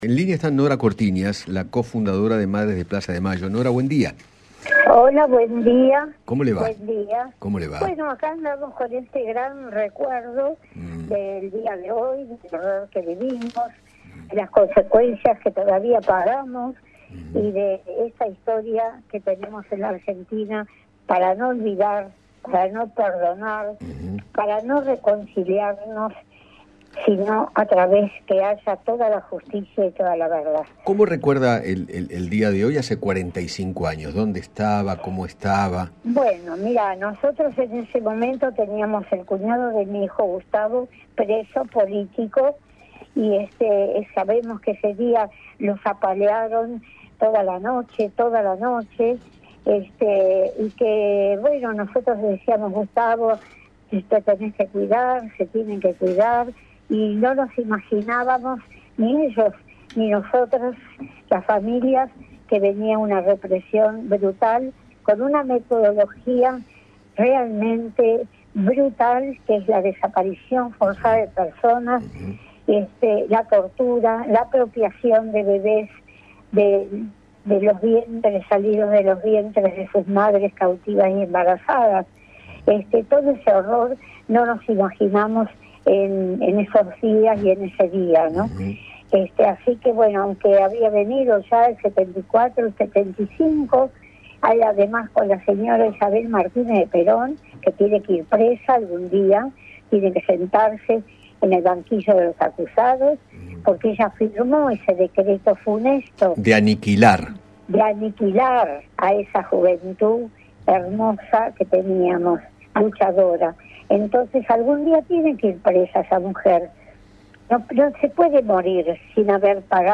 Nora Cortinas, co fundadora de Madres de Plaza de Mayo, dialogó con Eduardo Feinmann sobre el día nacional de la Memoria por la Verdad y la Justicia.